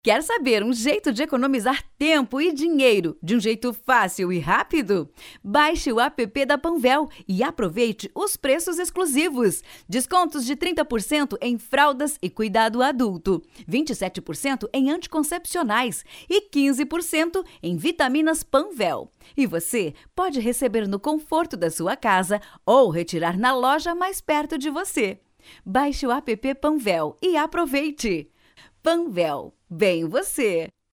Estilo Animado: